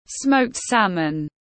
Cá hồi hun khói tiếng anh gọi là smoked salmon, phiên âm tiếng anh đọc là /sməʊkt ˈsæm.ən/
Smoked salmon /sməʊkt ˈsæm.ən/